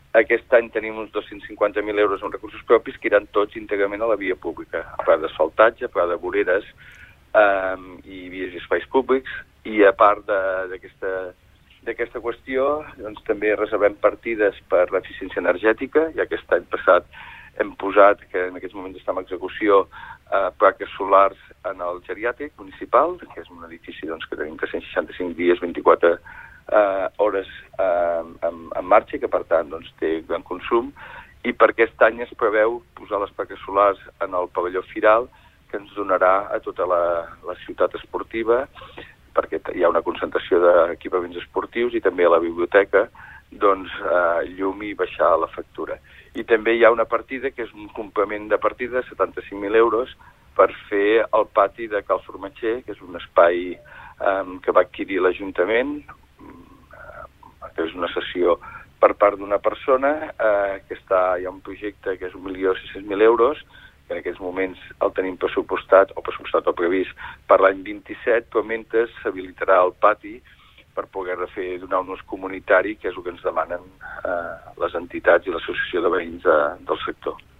Supermatí - entrevistes
I per parlar dels pressupostos i d’aquestes inversions ens ha visitat al Supermatí l’alcalde del mateix municipi, Òscar Aparicio.